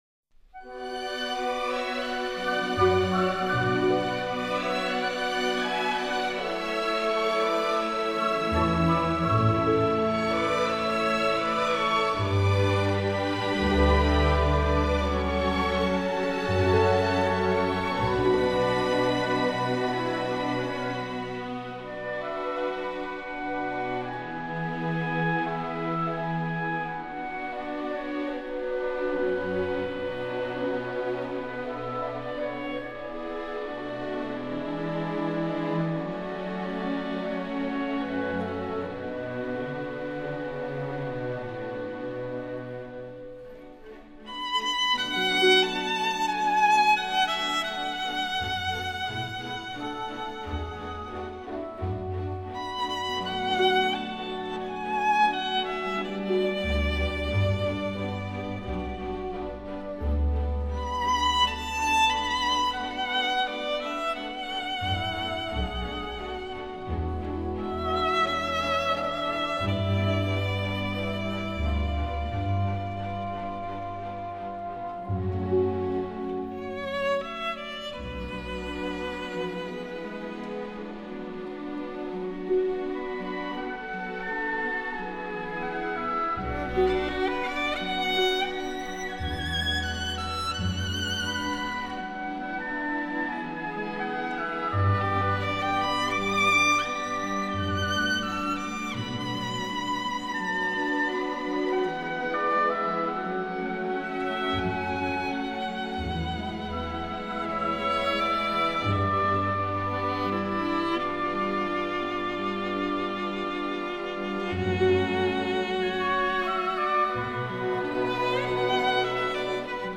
小提琴 管弦乐